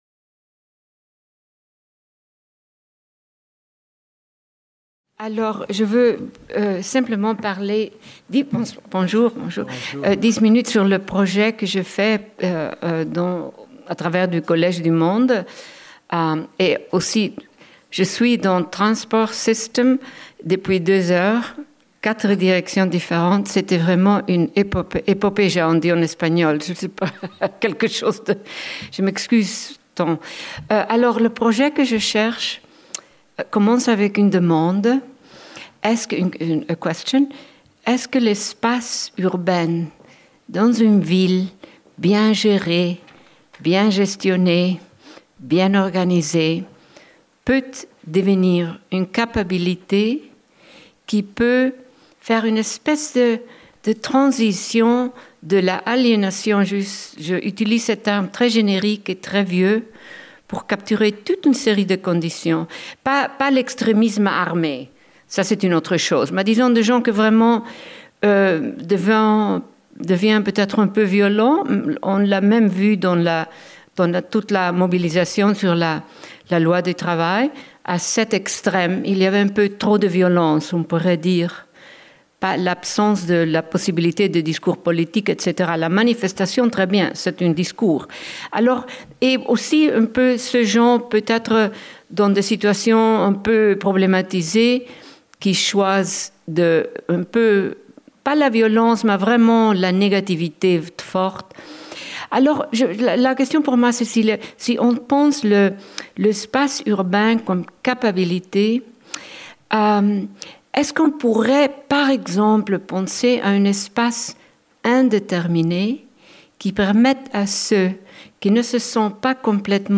Vendredi 20 mai - Philharmonie, Salle de conférence 16H25 Demain : The Global Street par Saskia SASSEN